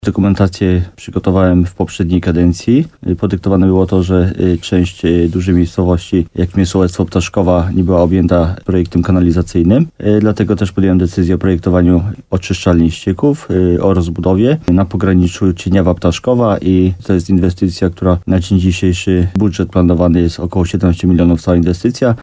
Jak mówił w programie Słowo za Słowo w radiu RDN Nowy Sącz wójt Jacek Migacz, to będzie jedna z priorytetowych inwestycji w tym roku.